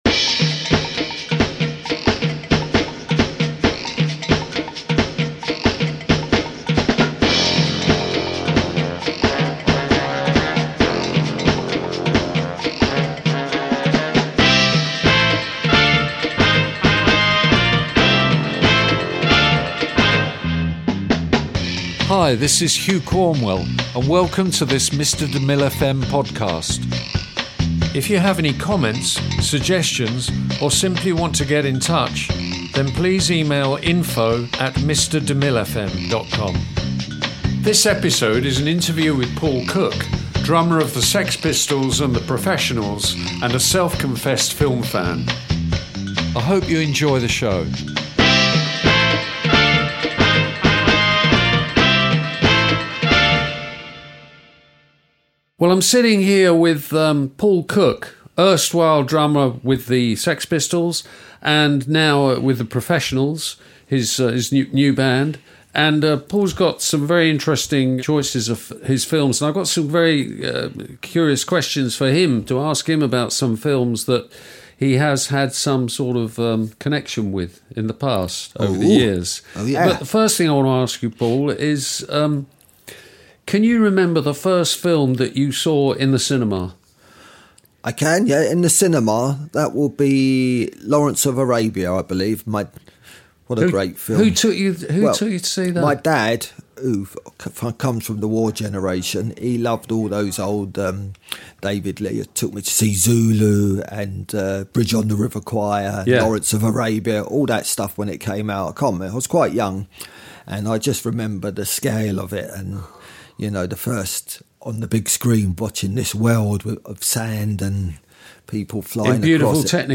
INTERVIEW PAUL COOK
This episode is an interview with Paul Cook, drummer of the Sex Pistols and the Professionals, and self confessed film fan.